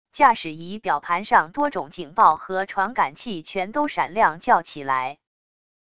驾驶仪表盘上多种警报和传感器全都闪亮叫起来wav音频